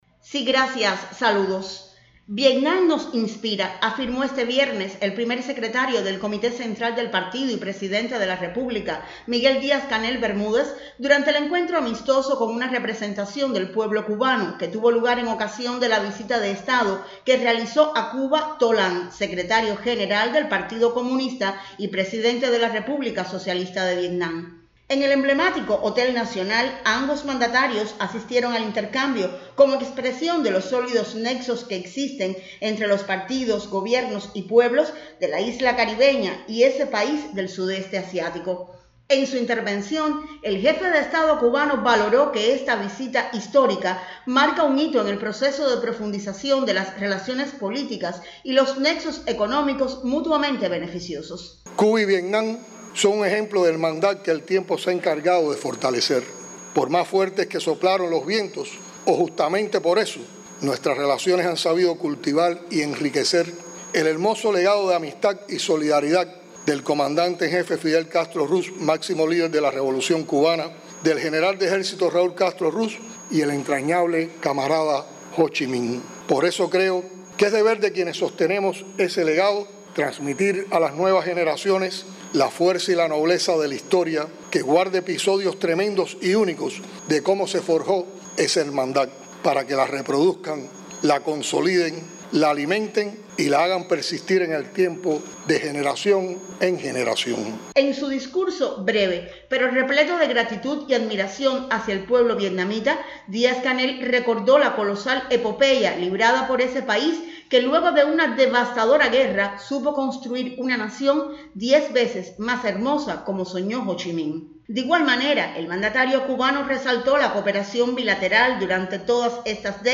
El Presidente Díaz-Canel y su homólogo vietnamita asistieron en la mañana de este viernes a un encuentro amistoso con representantes del pueblo cubano
acto_vietnam.mp3